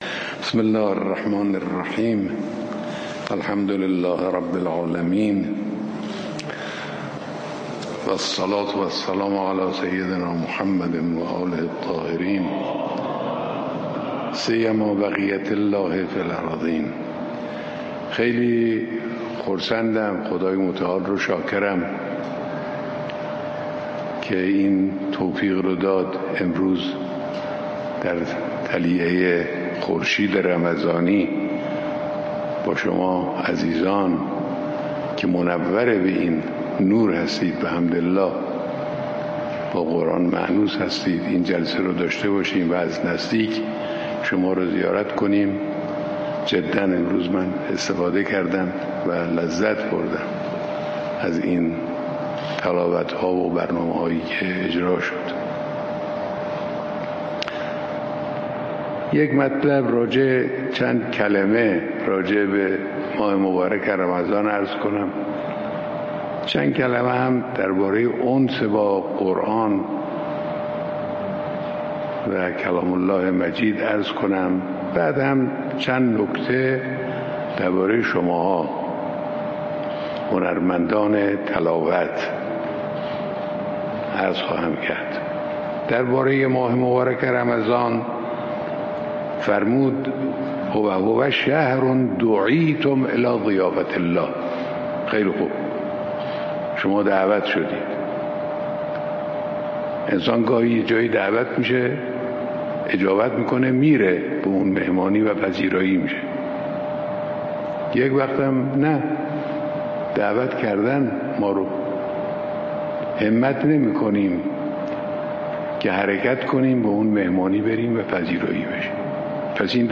مطابق رسم سالیان گذشته، محفل انس با قرآن کریم امروز 14 فروردین ماه در نخستین روز از ماه مبارک رمضان با حضور رهبر انقلاب اسلامی، در حسینیه امام خمینی(ره) برگزار شد. در ادامه بیانات حضرت آیت الله خامنه ای را در این محفل می‌شنوید.